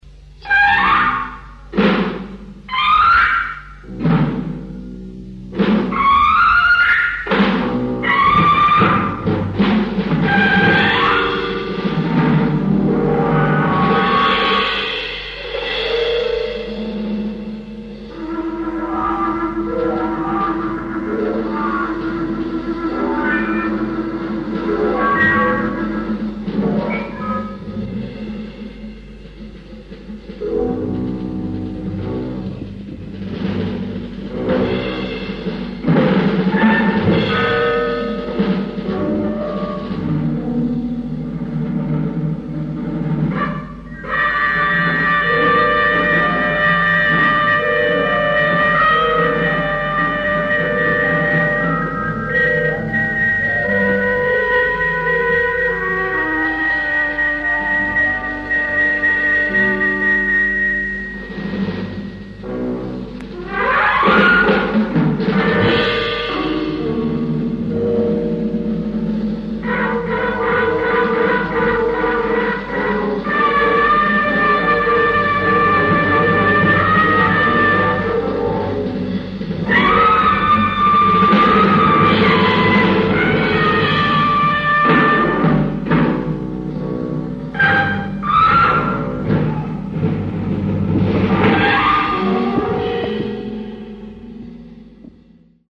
※試聴用に実際より音質を落としています。